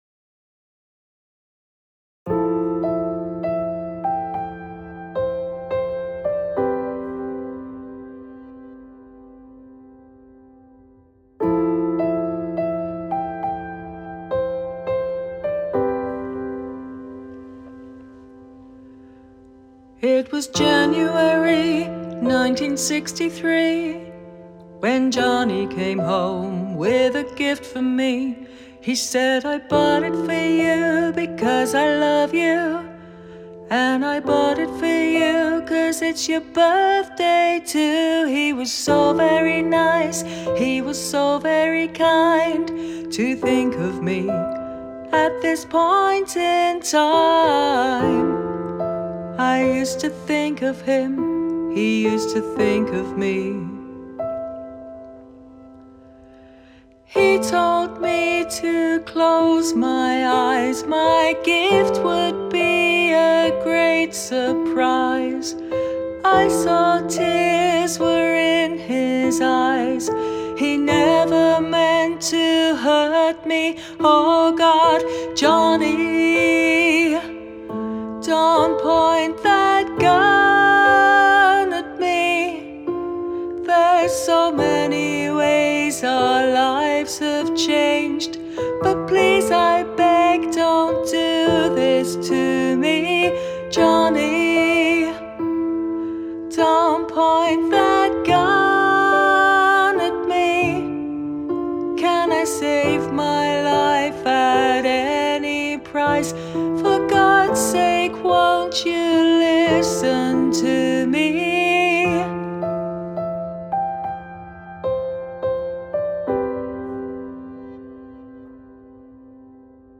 Vocals remind of Captain and Tennille.